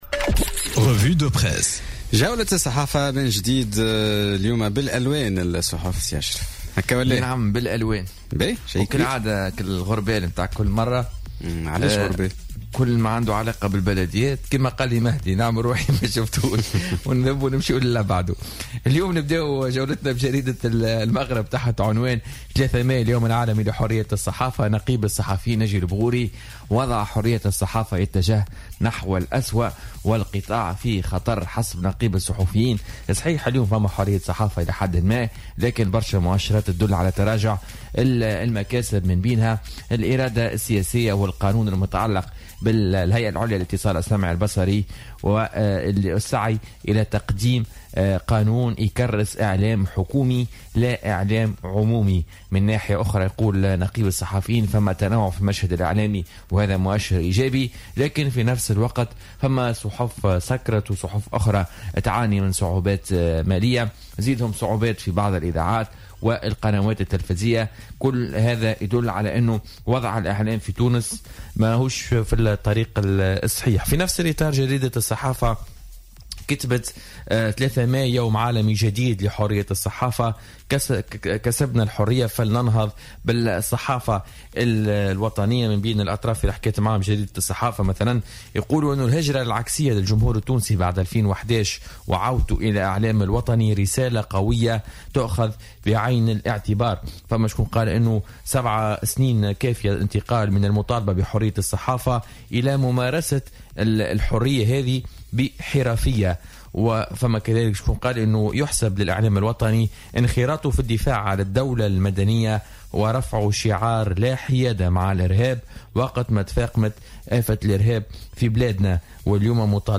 Revue de presse du jeudi 03 mai 2018